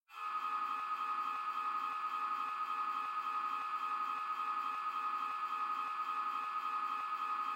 科幻引擎 " 船只受损爆炸产生的火花2
描述：另一个版本的爆炸损坏的发动机，有火花。 这一次，发动机在可怕地跳动着。
Tag: 损害 发动机 科幻 飞船 火花